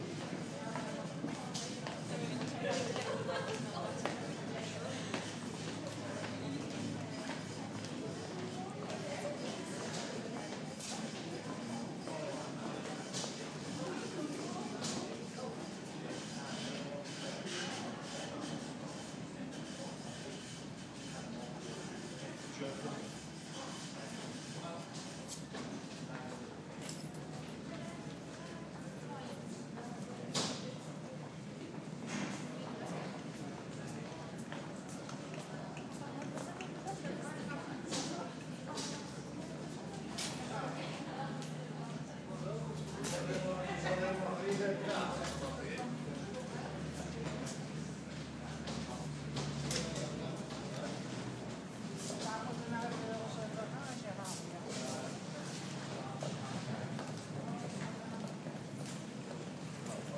Somewhere, useless background ambient noise
79714-somewhere-useless-background-ambient-noise.mp3